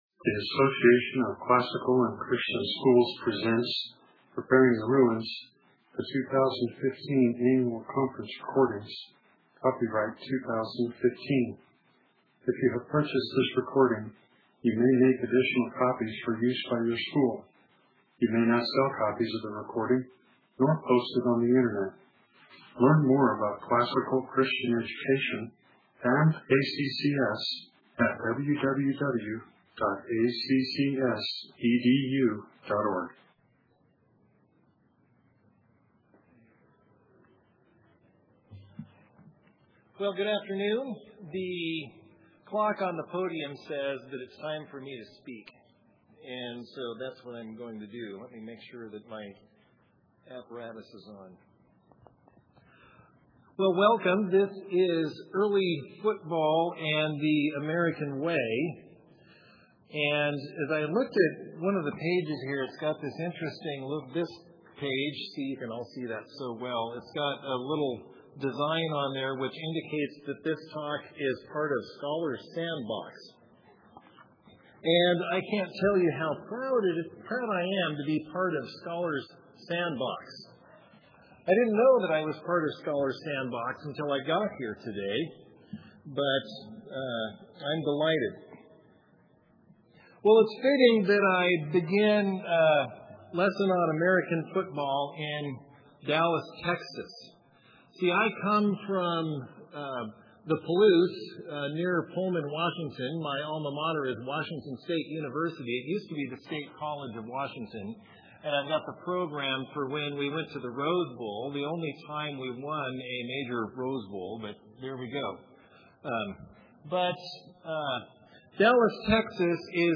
2015 Workshop Talk, 1:02:53, All Grade Levels, History